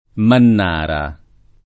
[ mann # ra ]